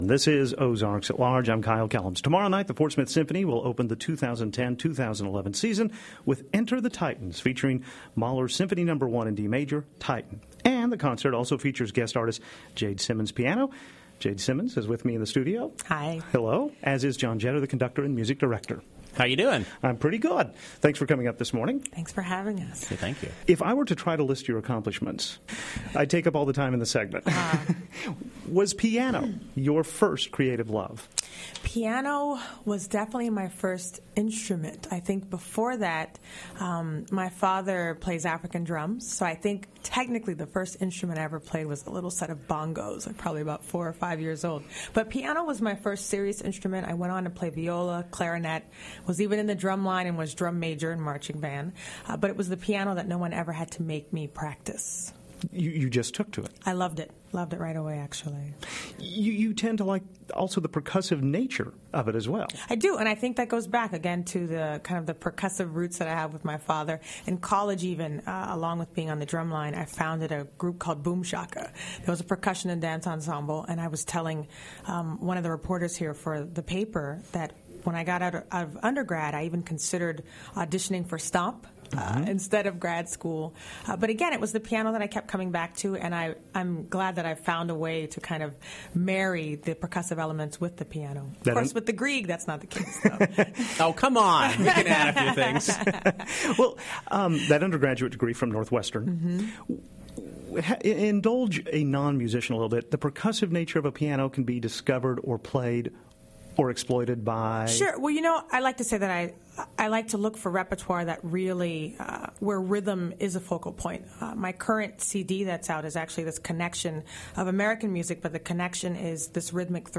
This morning she came to the Carver Center for Public Radio.